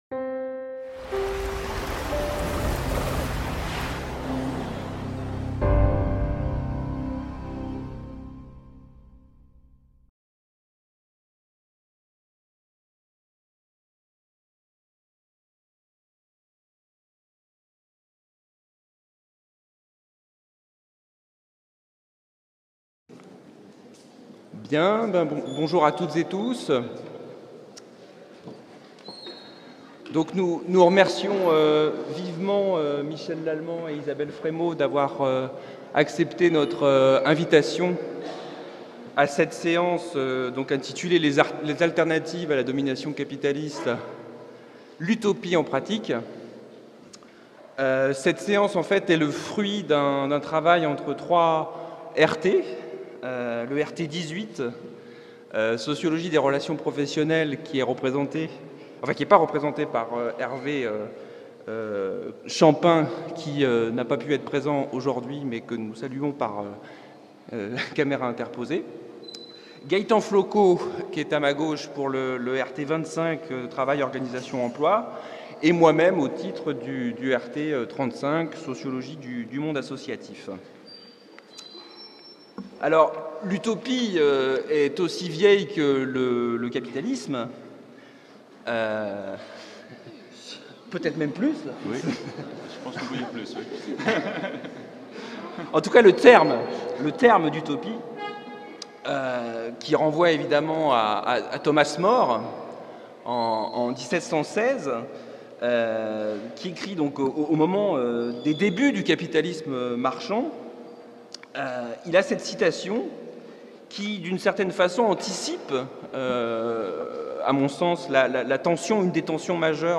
L'UFR de Sociologie et le Centre Nantais de Sociologie (CENS) de l’Université de Nantes accueillaient du 2 au 5 septembre 2013 le 5e congrès international de l'association française de sociologie.